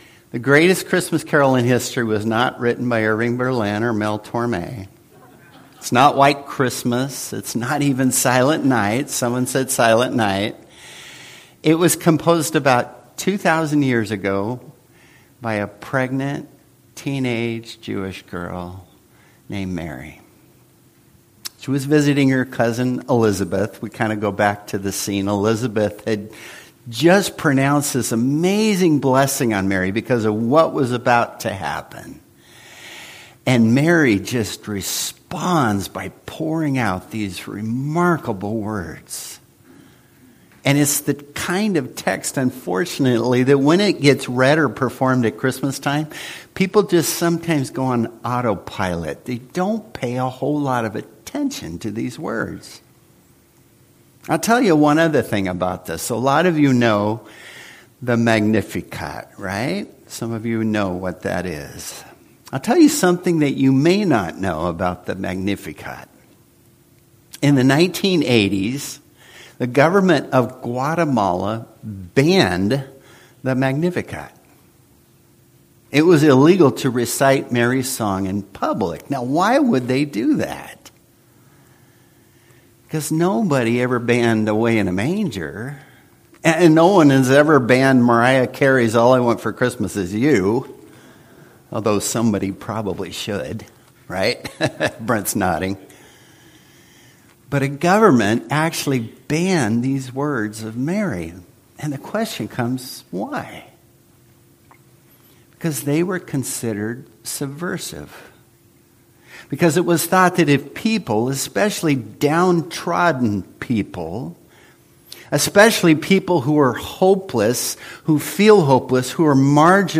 Service Type: Worship Service